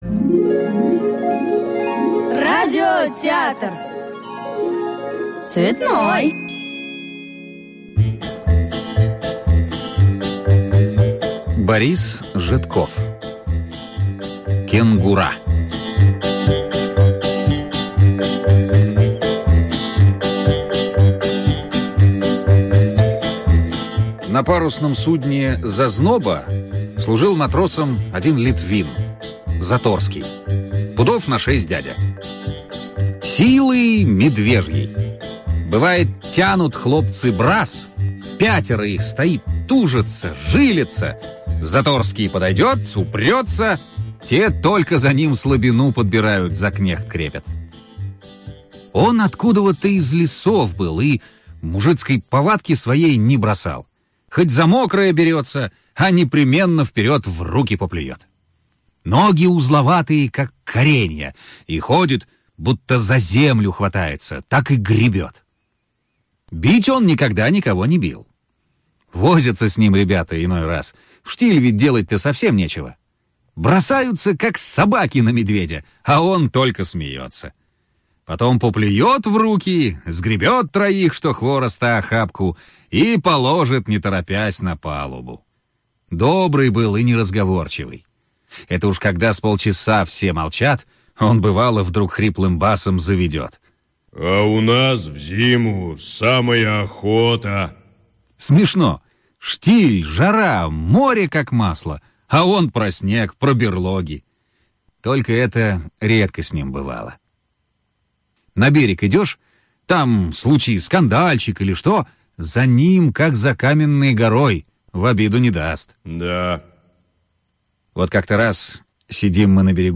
Кенгура - аудио рассказ Житкова Б.С. Однажды моряки, отдыхающие на берегу в чужой стране, решили сходить в местный цирк...